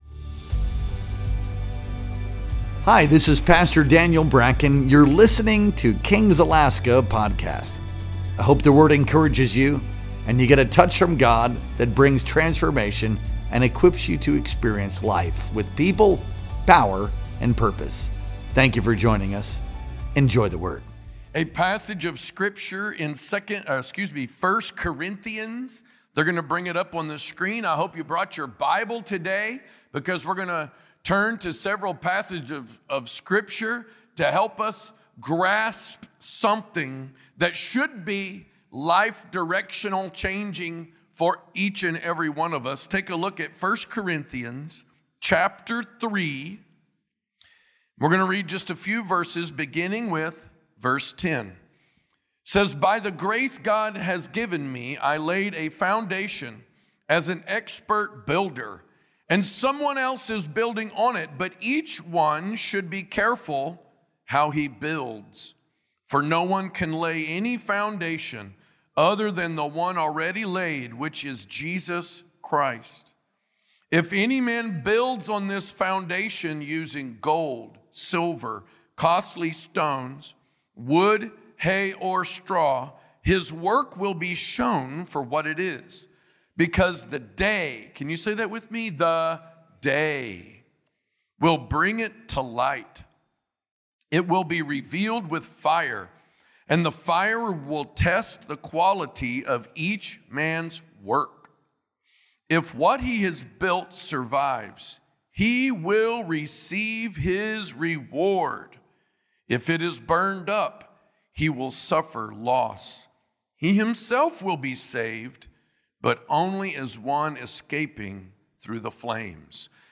Our Sunday Morning Worship Experience streamed live on November 2nd, 2025.